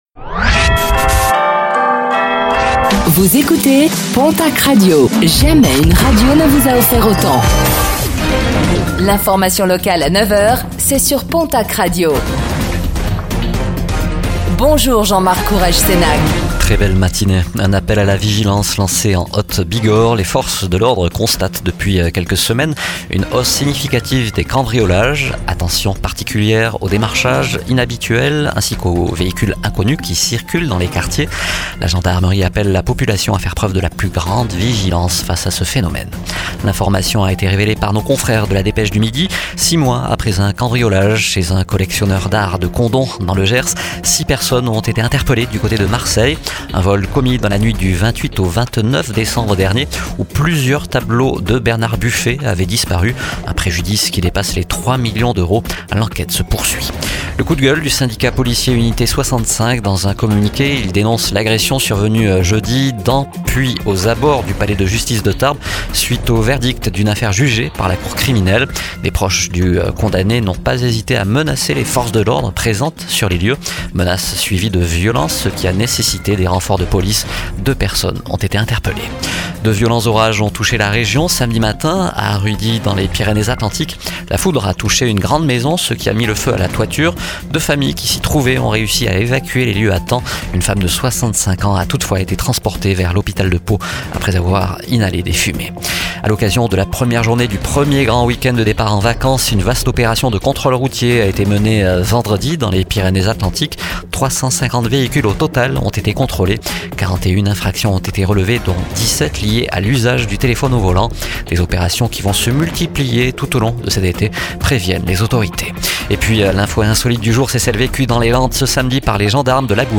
09:05 Écouter le podcast Télécharger le podcast Réécoutez le flash d'information locale de ce lundi 07 juillet 2025